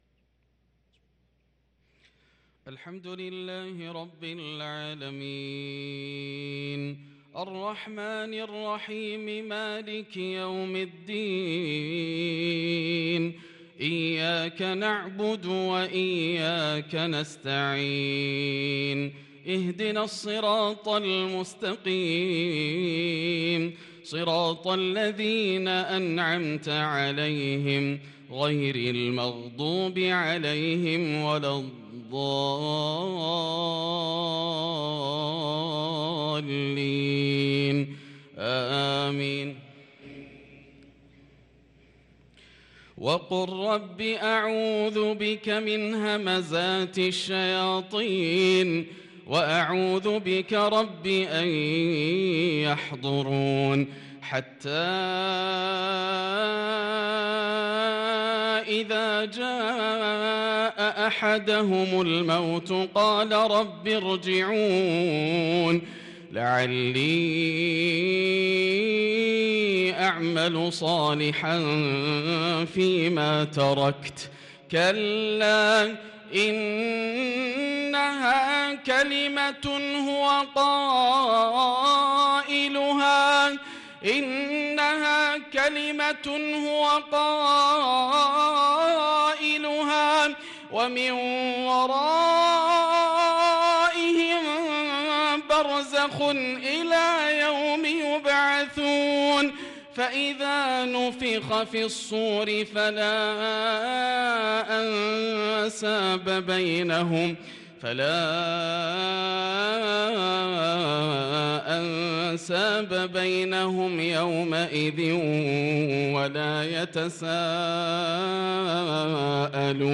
صلاة العشاء للقارئ ياسر الدوسري 24 شعبان 1443 هـ
تِلَاوَات الْحَرَمَيْن .